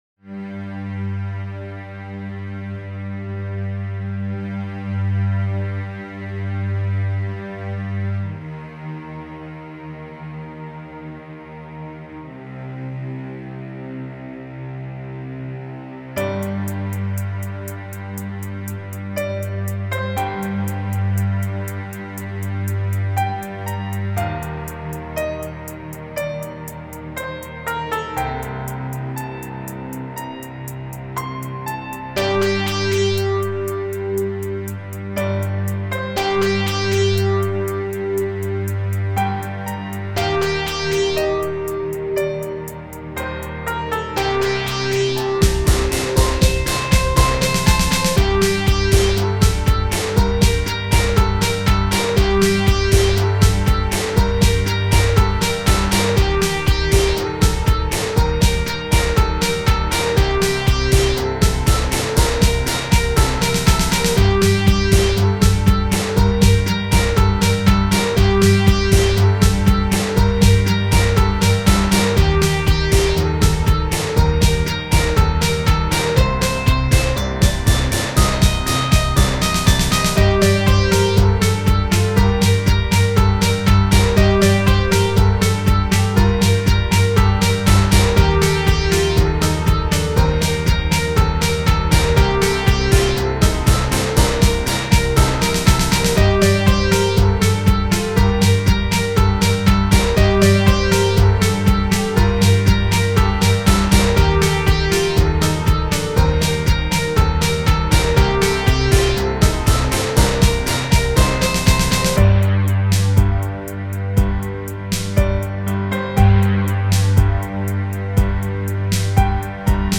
These tracks have been sequenced on Linux with Rosegarden.
All the drums are performed by Hydrogen.